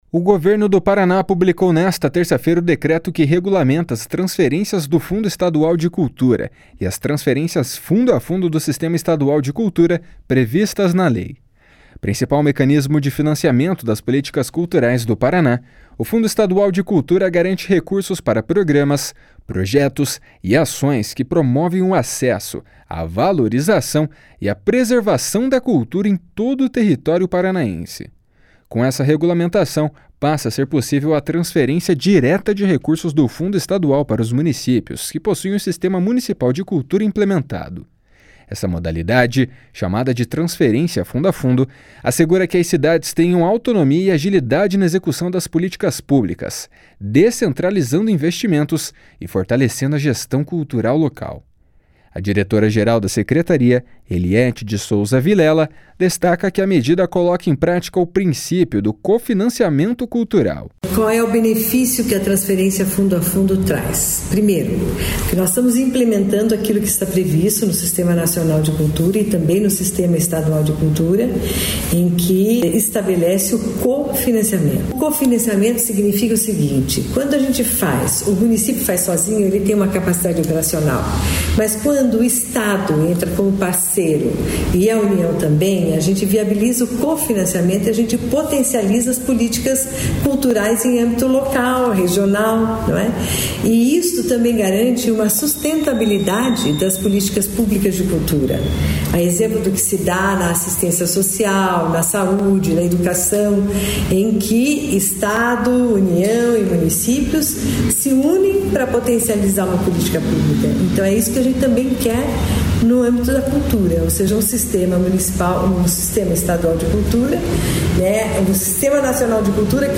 A diretora-geral da Secretaria, Elietti de Souza Vilela, destaca que a medida coloca em prática o princípio do cofinanciamento cultural. // SONORA ELIETTI DE SOUZA //